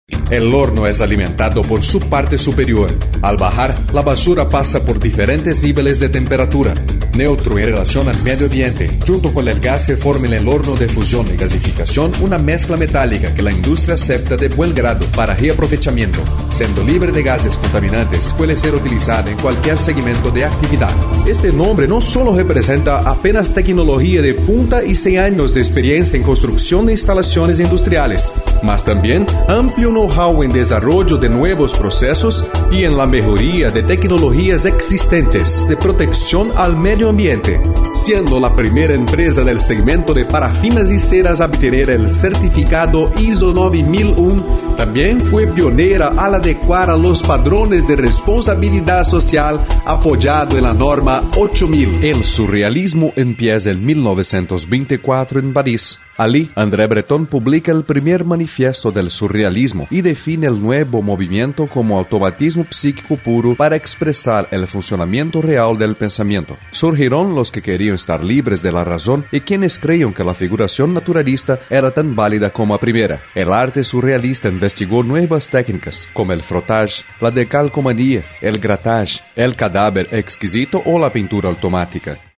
It can be fun, sexy, professional or smooth depending on the type of recording.
Sprechprobe: Sonstiges (Muttersprache):